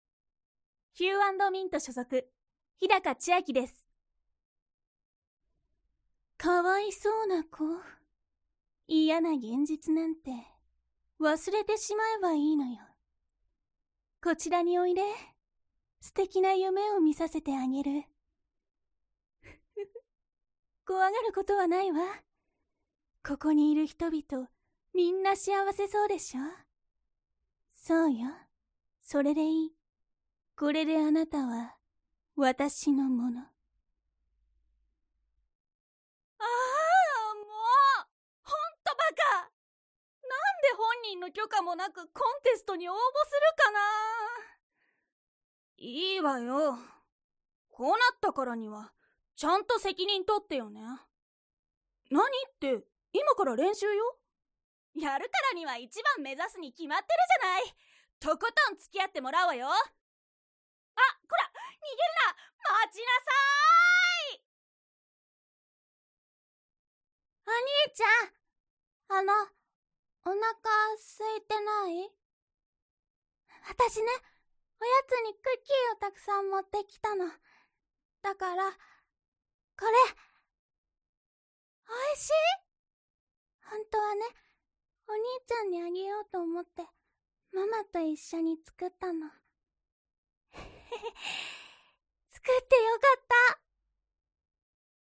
■ボイスサンプル： https